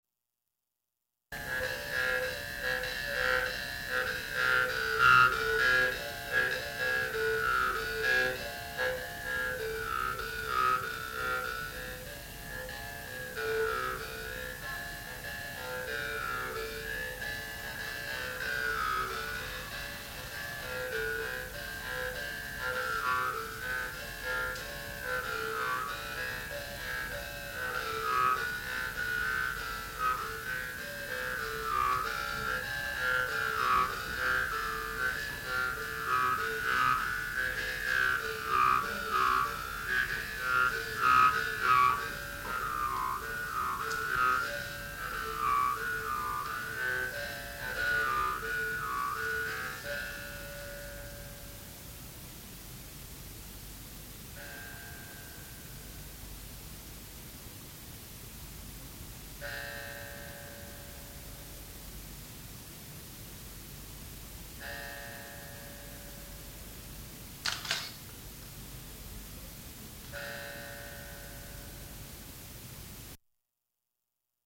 Jew's Harp tune number 4719